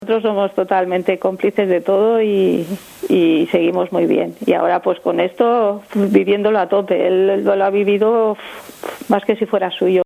la emoción vibra en su voz (formato MP3) al añadir:“nos conocemos de toda la vida y prácticamente él me ha creado; esto sí que es una media naranja que no funciona la una sin la otra”.